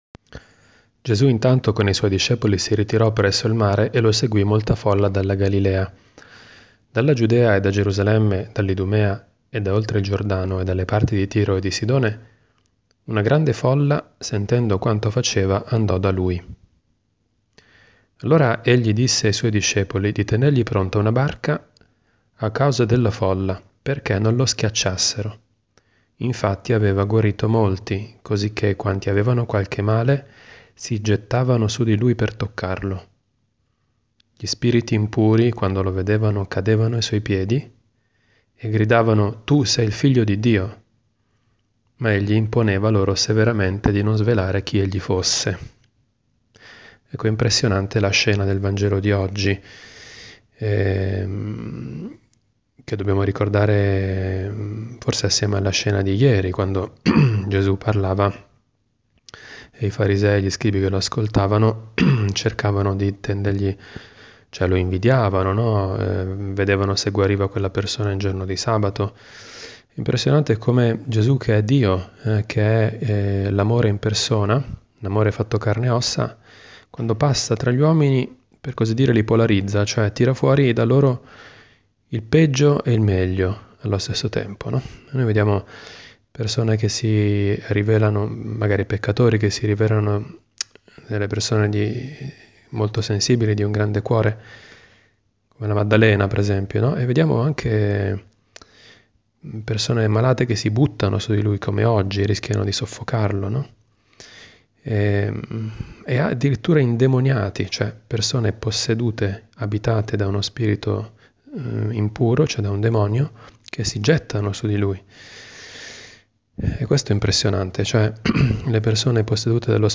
Commento al vangelo (Mc 3,7-12) del 18 gennaio 2018, giovedì della II domenica del Tempo Ordinario.